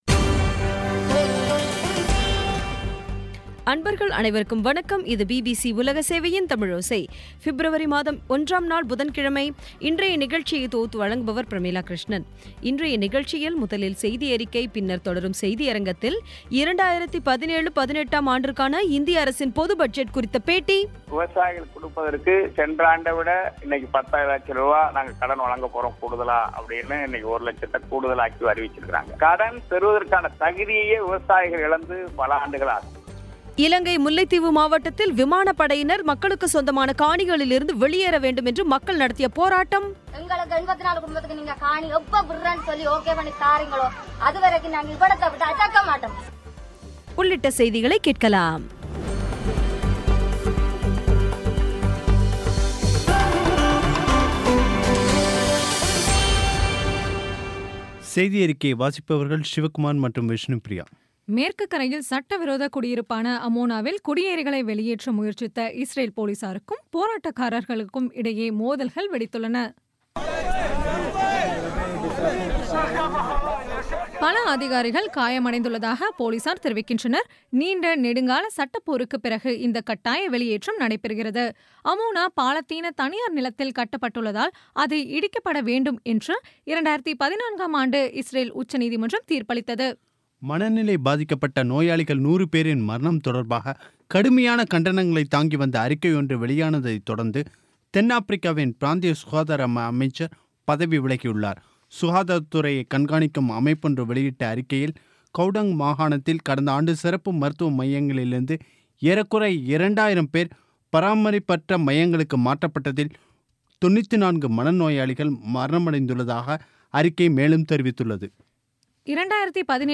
இன்றைய நிகழ்ச்சியில் முதலில் செய்தியறிக்கை, பின்னர் தொடரும் செய்தியரங்கில் 2017-18 ஆம் ஆண்டுக்கான இந்திய அரசின் பொது பட்ஜெட் குறித்த பேட்டி முல்லைத்தீவு மாவட்டத்தில் விமானப்படையினர் மக்களுக்குச் சொந்தமான காணிகளில் இருந்து வெளியேற வேண்டும் என்று மக்கள் நடத்திய போராட்டம் பற்றிய செய்தி ஆகியவை கேட்கலாம்